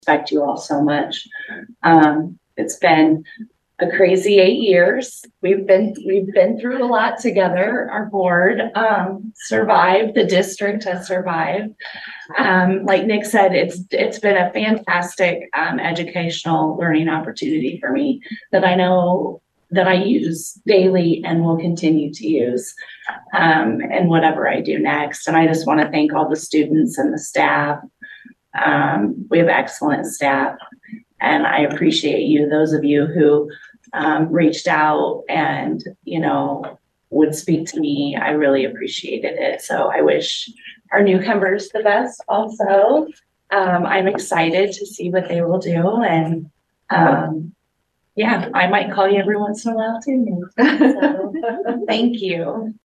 (Atlantic) The Atlantic School Board held a final meeting of the retiring Board and conducted an organizational meeting with the new Board on Wednesday evening.
Jenny Williams thanked the Board and noted her time spent on the Board for the past eight years was a learning experience she will carry with her moving forward.